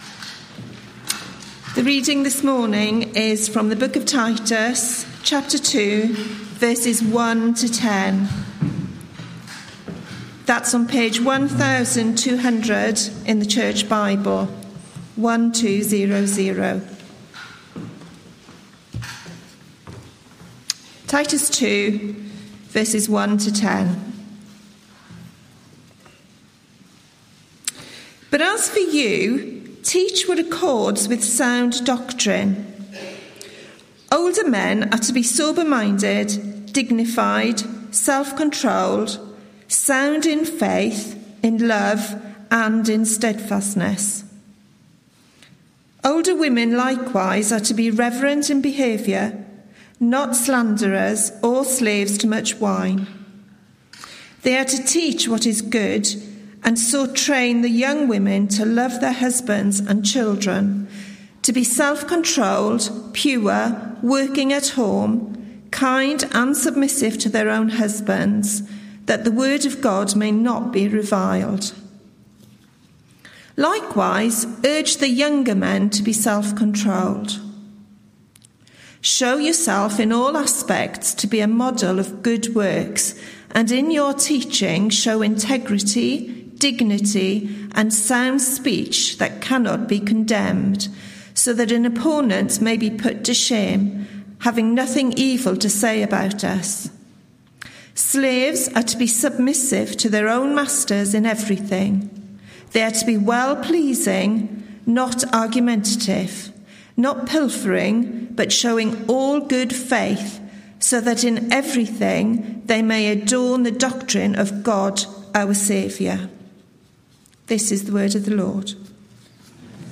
Media for Morning Meeting on Sun 06th Jul 2025 10:30 Speaker
AM Theme: Sermon Search media library...